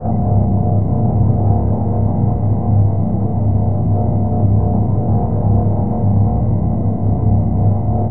Engine_high.ogg